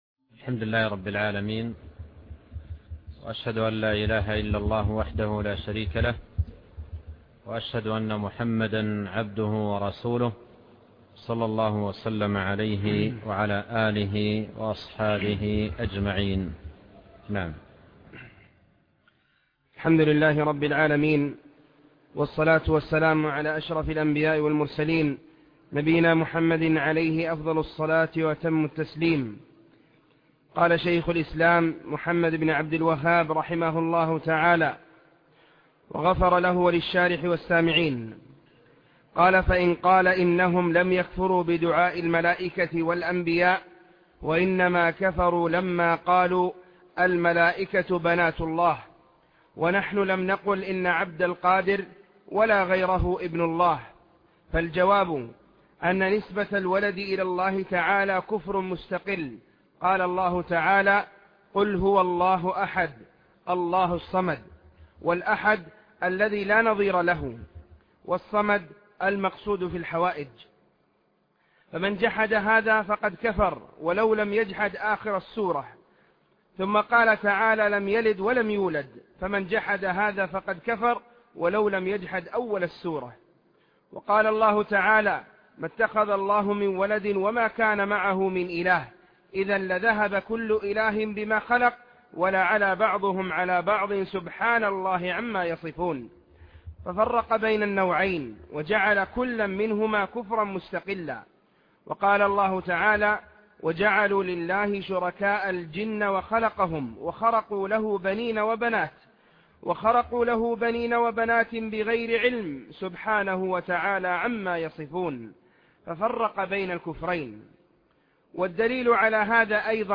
الدرس 4